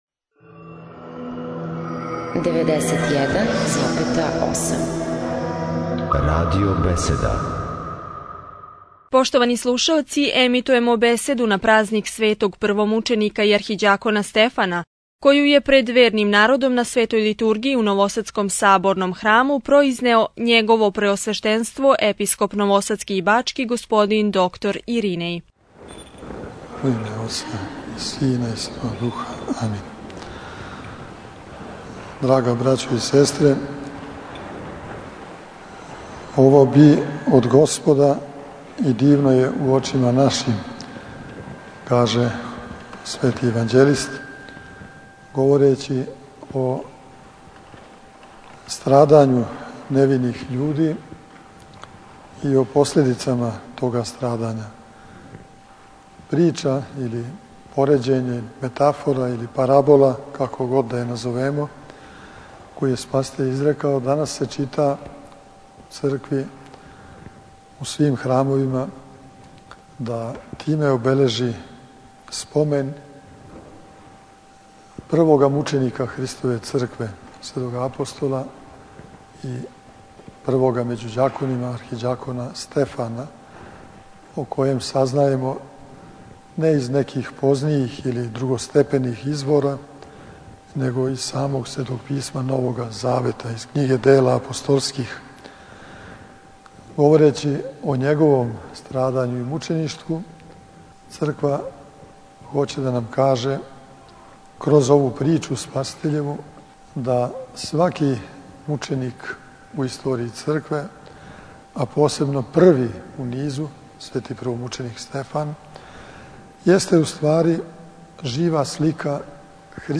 Трећега дана Божића, на празник Светог првомученика и архиђакона Стефана, 9. јануара 2012. године, Његово Преосвештенство Епископ бачки Господин др Иринеј служио је свету архијерејску Литургију у новосадском Светогеоргијевском храму и поучио све учеснике данашњег свештеног евхаристијског сабрања надахнутом архипастирском беседом.